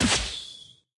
Media:Bo_base_atk_1.wav 攻击音效 atk 初级和经典及以上形态攻击音效
Bo_base_atk_1.wav